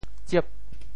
潮州发音 潮州 zieb8